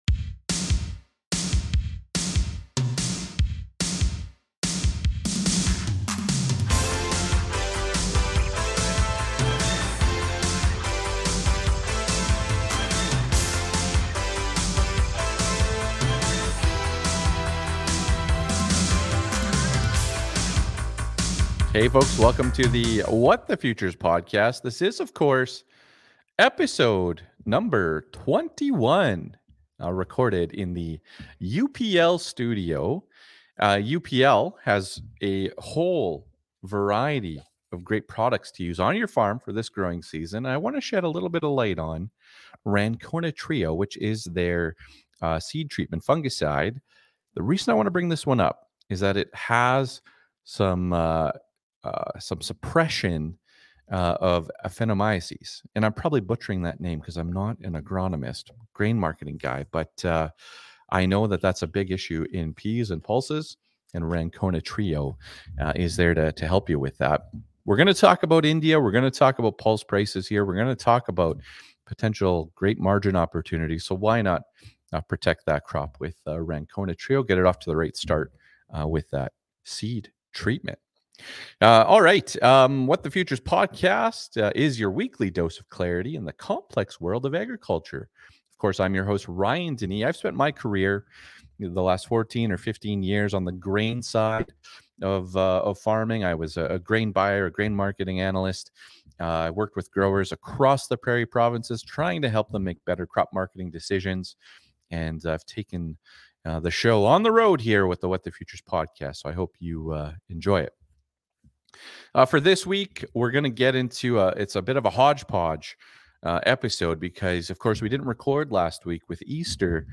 Episode #21 was recorded in the UPL Studio!